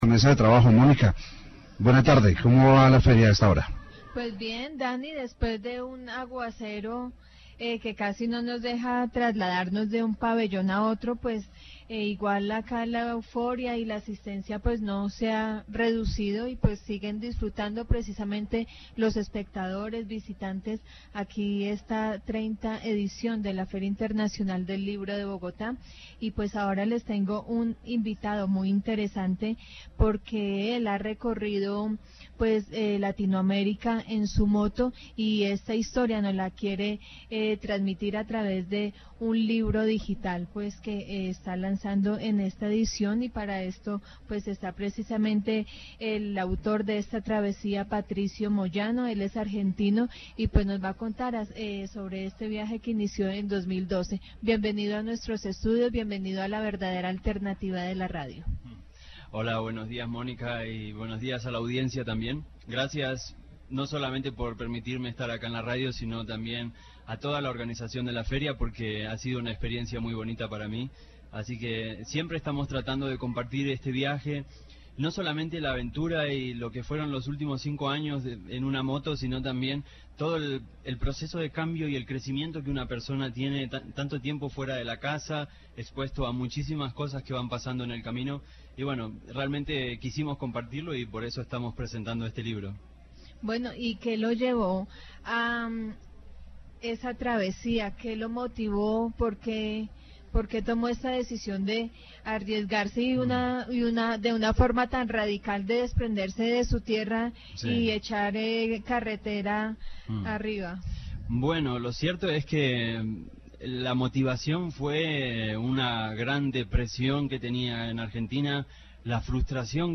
Feria del Libro 2017.